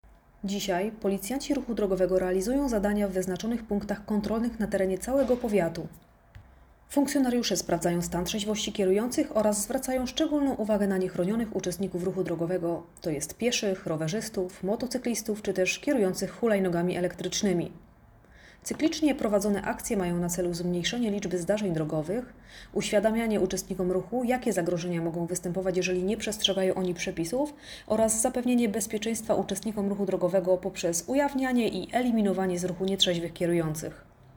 Nagranie audio Działania trzeźwość i NURD na drogach powiatu mieleckiego, mówi